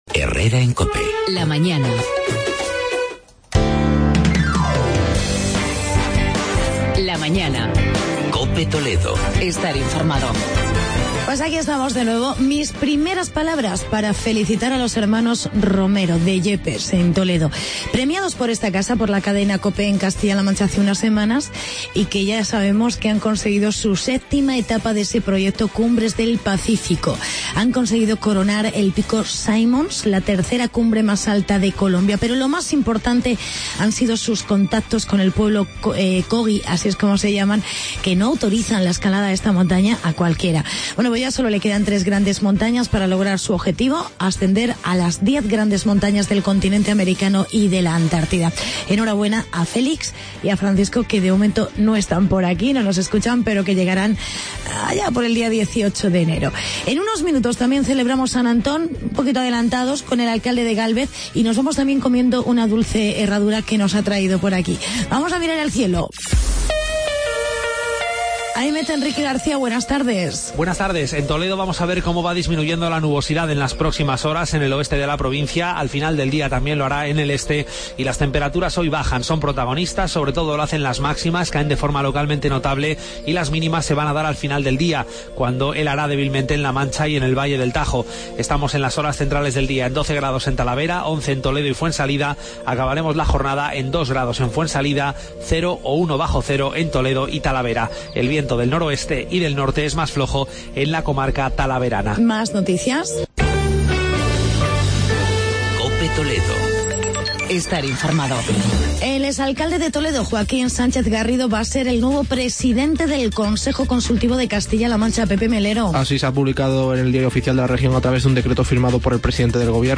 Actualidad y entrevista con el alcalde de Gálvez sobre Las Luminarias de San Antón.